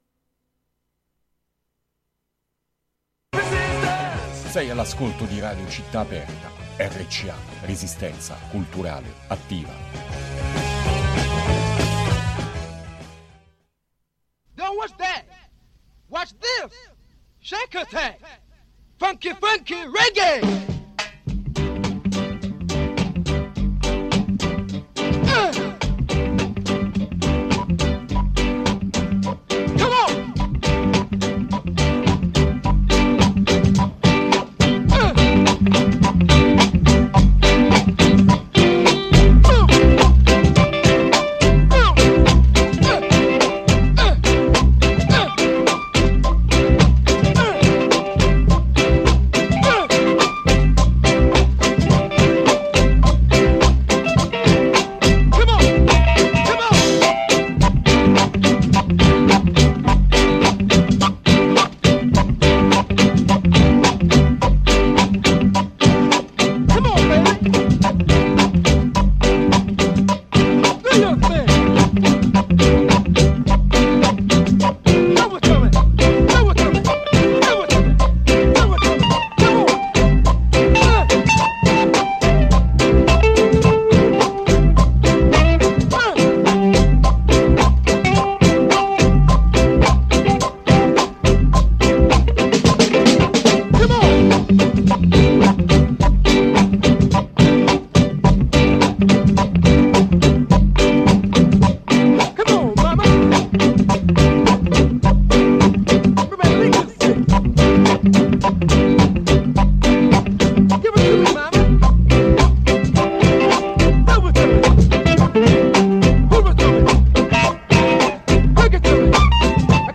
nella nuova scena psichedelica
un po' di giamacan classics